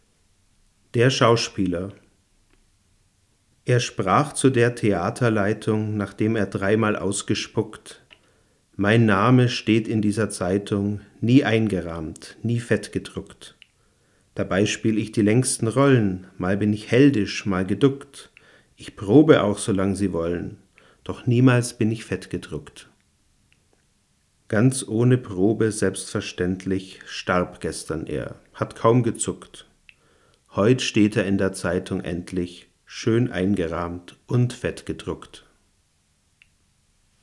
Um das gefürchtete Rauschen zu vergleichen, habe ich ein dynamisches Mikrofon benutzt und aus 60 cm Abstand besprochen.
Mikrofon am PreAmp M-Audio DMP2 (dann über Line-Eingang in Tascam DR-40)